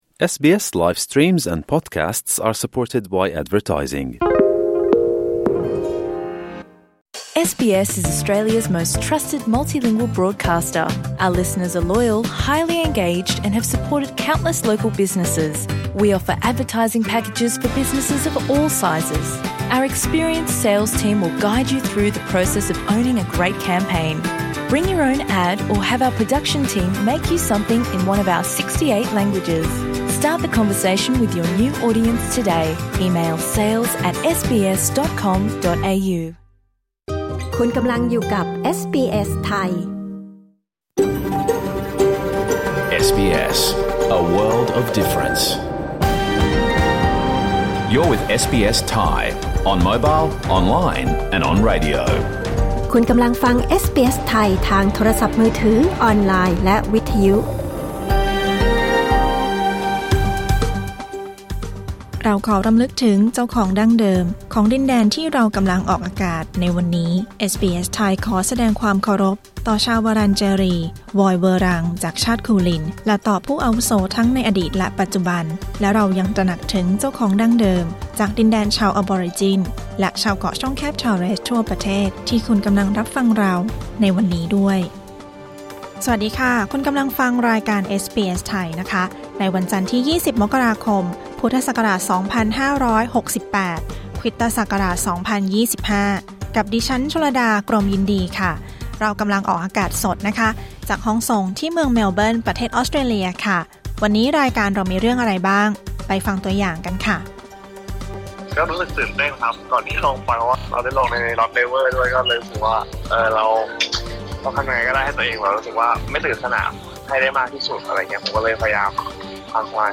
รายการสด 20 มกราคม 2568